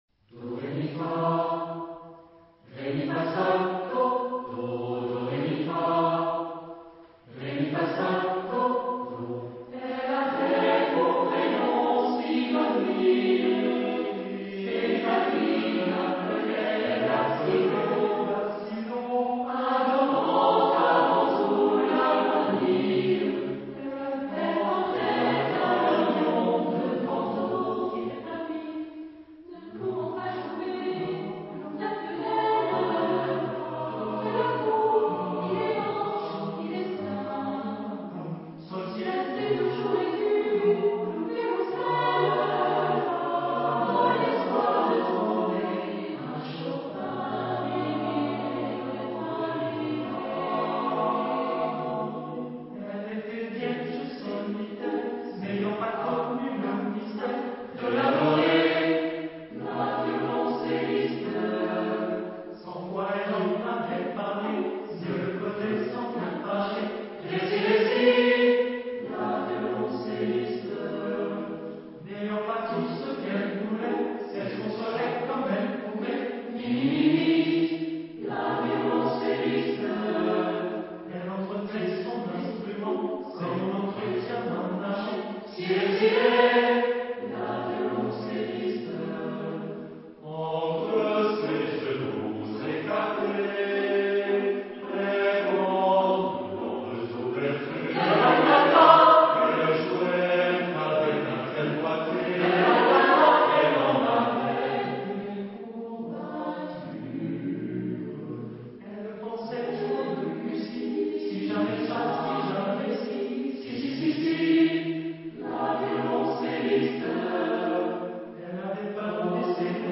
Genre-Style-Forme : Chanson ; Fantaisie ; Profane ; Berceuse
Type de choeur : SATB  (4 voix mixtes )
Tonalité : fa majeur